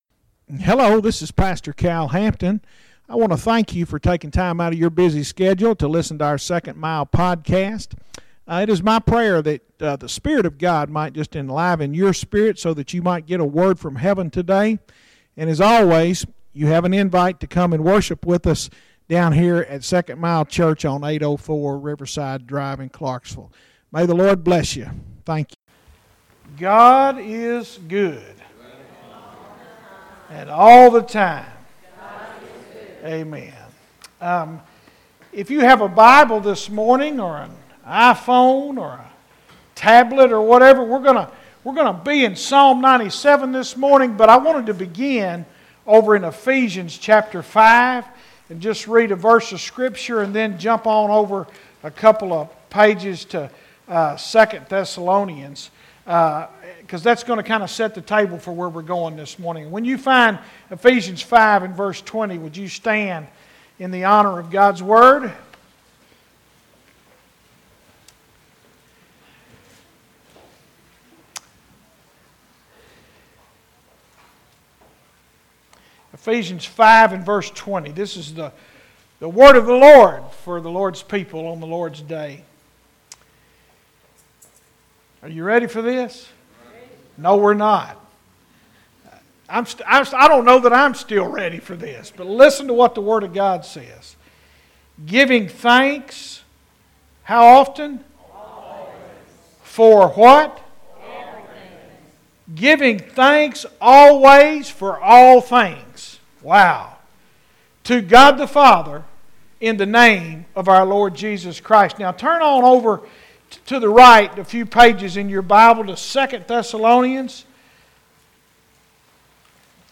SERMONS - 2nd Mile Church